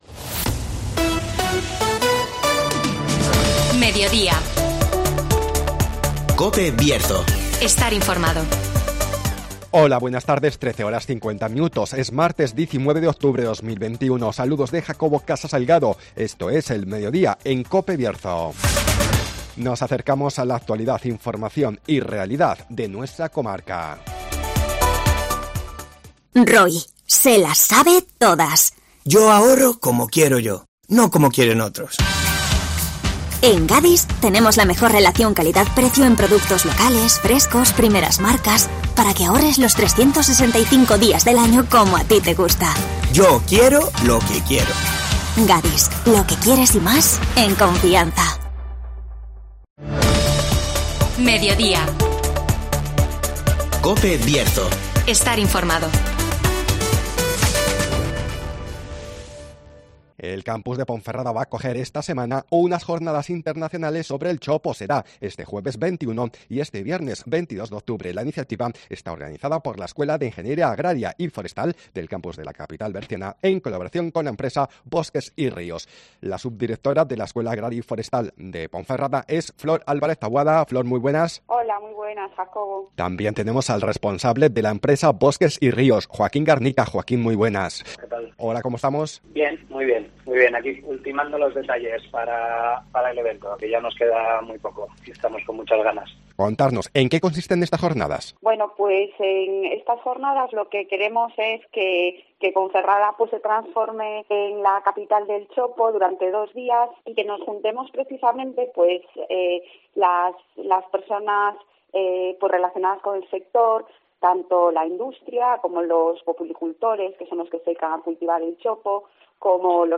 'Populus360' convertirá el Campus de Ponferrada en la capital del chopo (Entrevista